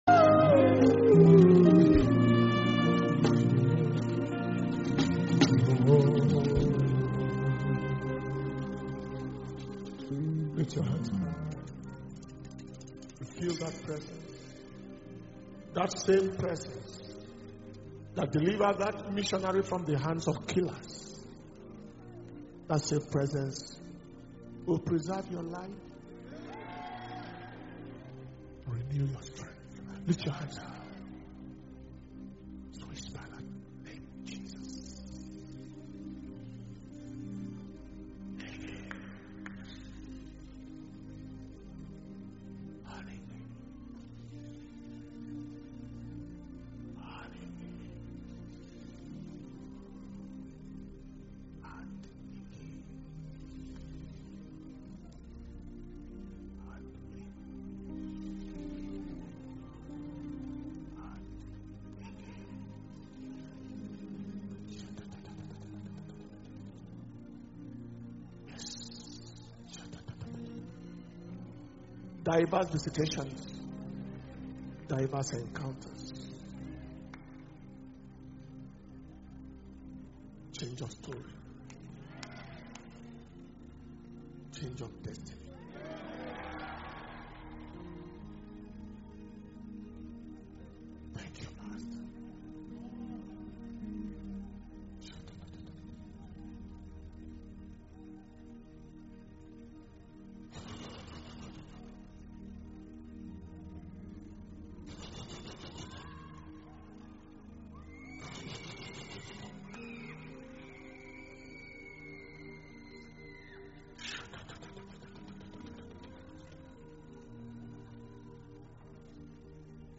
Power Communion Service